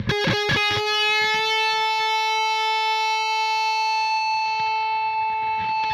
Les Paul, cranked Marshall stack, big chord, epic pickslide, blossoming into beautiful sustaining natural feedback.